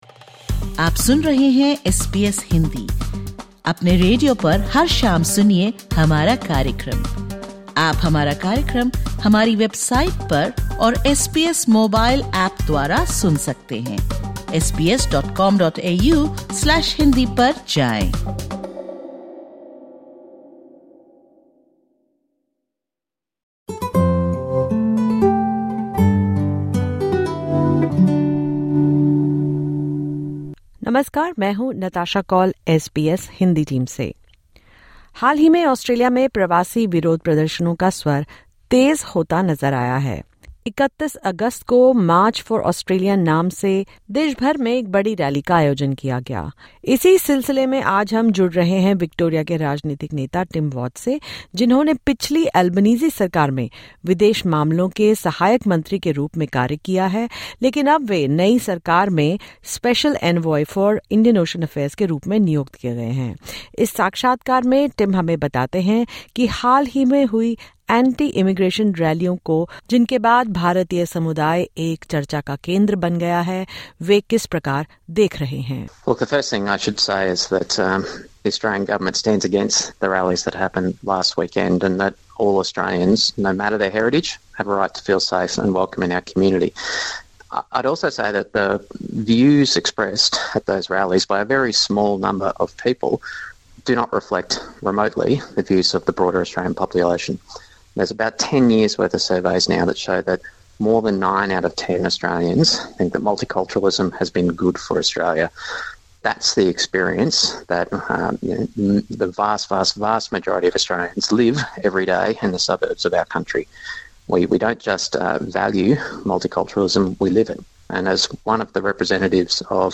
Liberal Senator Jacinta Nampijinpa Price's claim that the government is accepting "large numbers" of Indian migrants into Australia to bolster Labor's vote has been strongly rebuffed by the Special Envoy for Indian Ocean Affairs, Tim Watts. Listen to this interview in which he shares how he is engaging with the community following recent anti-immigration rallies and their turnout.